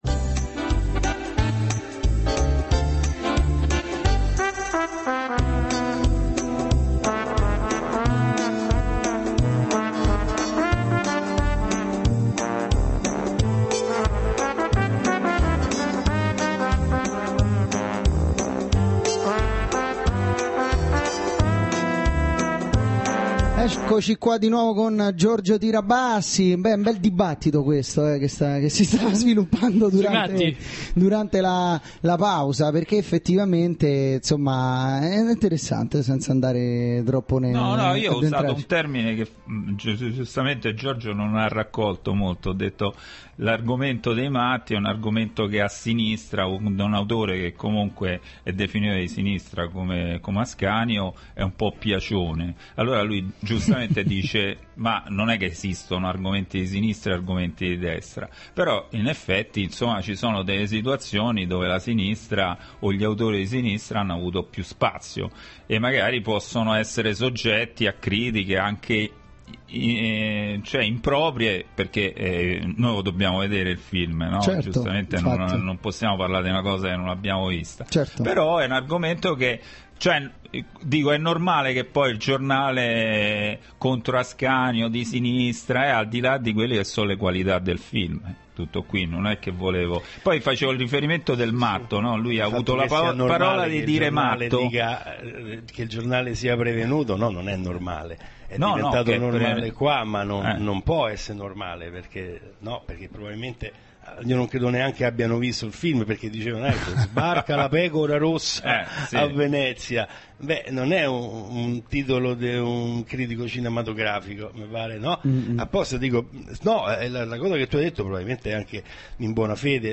Giorgio Tirabassi ospite a Radiosei 06/09/2010 (prima parte)
ascolta_giorgio_tirabassi_ospite_a_radiosei_prima.mp3